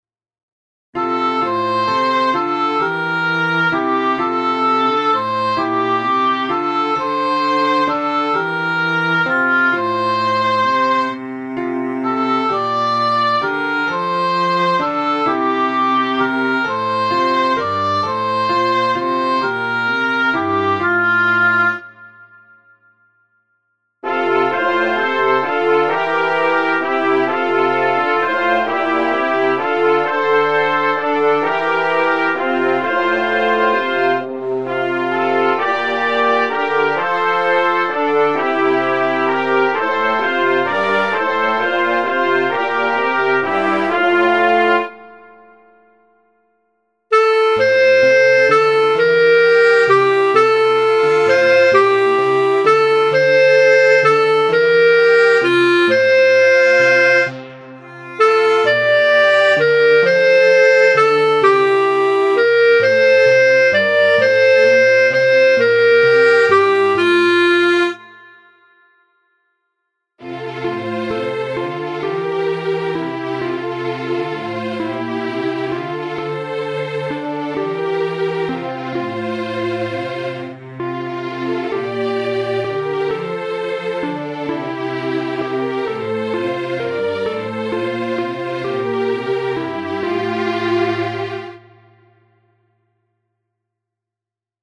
Chorsatz, 3-stimmig F-Dur